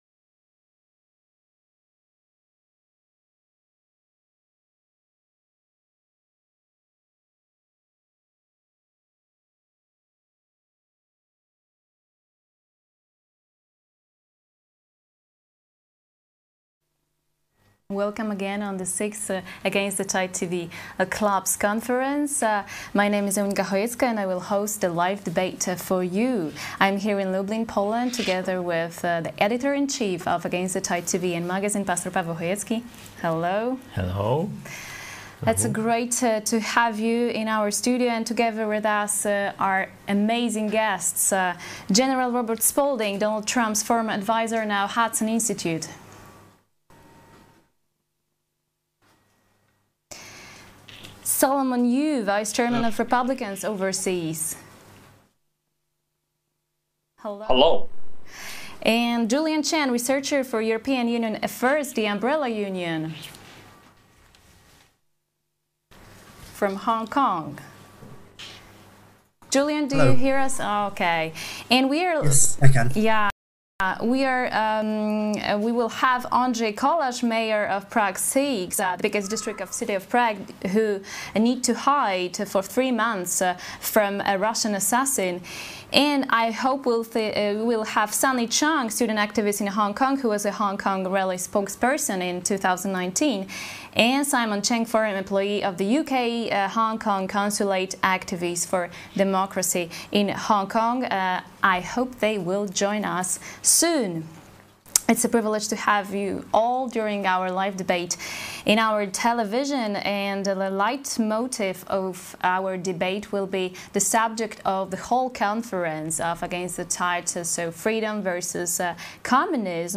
International panel "Freedom vs. Communism" - Will the China's CCP rob us of our freedoms?